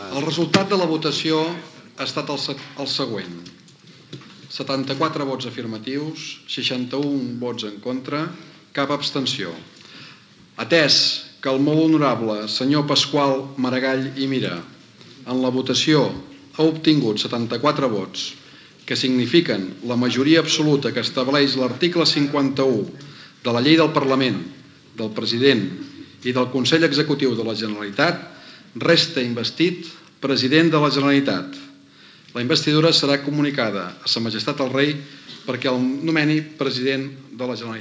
El President del Parlament de Catalunya Ernest Benach anuncia que el candidat Paqual Maragall, del Partit Socialista de Catalunya, ha estat el més votat per ser president de la Generalitat
Informatiu